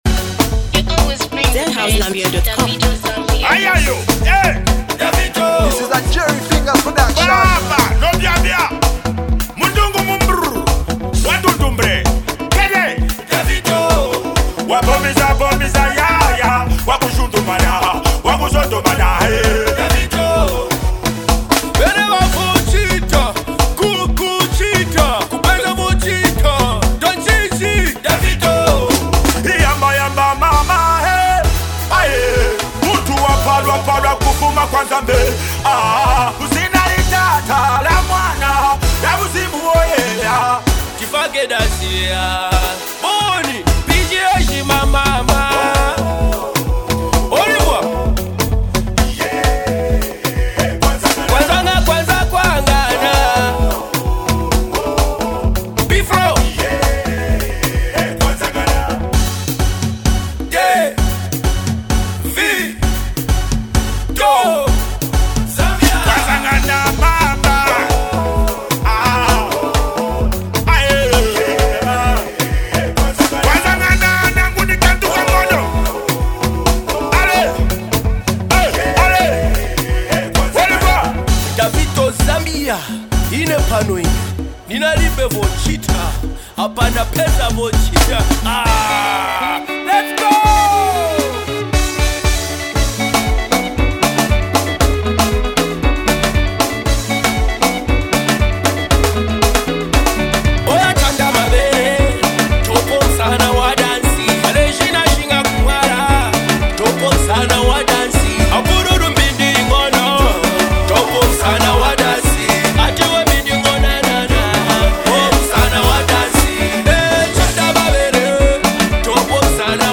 dancehall banger